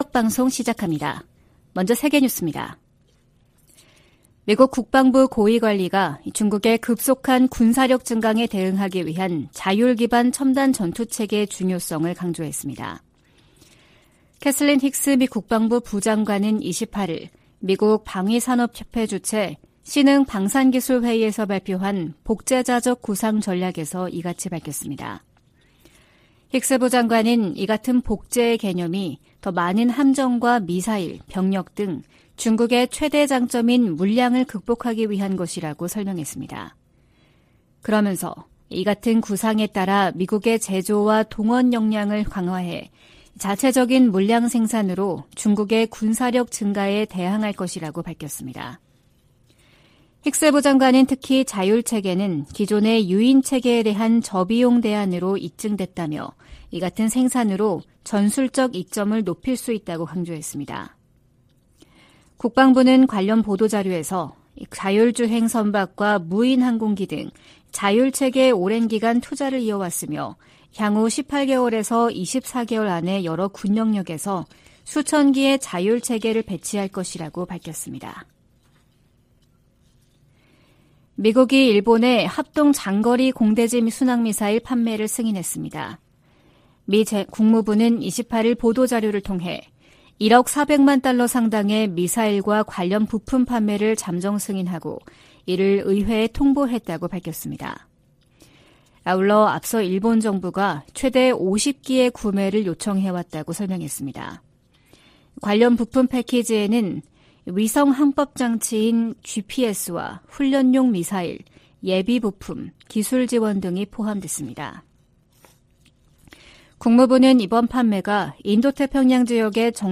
VOA 한국어 '출발 뉴스 쇼', 2023년 8월 30일 방송입니다. 유엔 안보리의 북한 정찰위성 발사 시도 대응 공개회의에서 미한일 등은 반복적으로 이뤄지는 도발을 규탄했습니다. 미 국무부가 후쿠시마 원전 오염처리수 방류 결정을 지지한다는 입장을 밝혔습니다. 북한이 신종 코로나바이러스 감염증 사태 이후 3년 7개월여만에 국경을 개방했습니다.